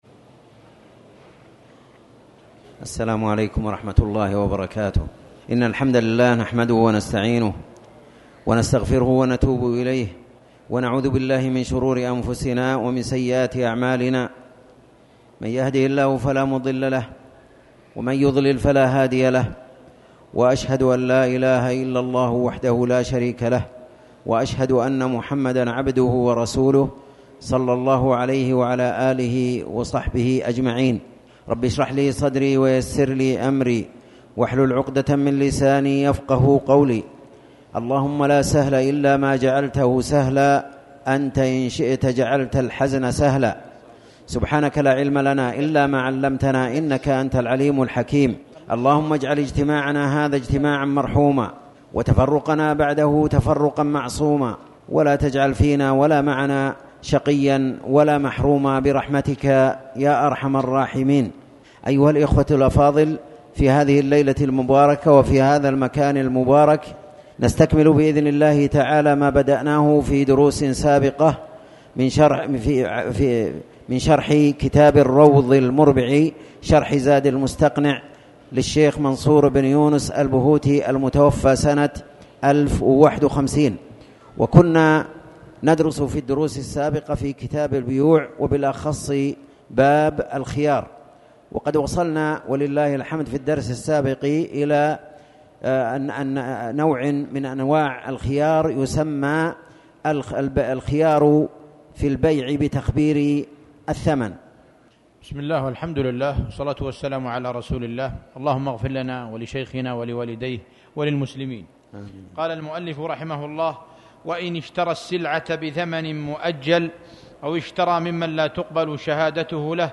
تاريخ النشر ٧ صفر ١٤٤٠ هـ المكان: المسجد الحرام الشيخ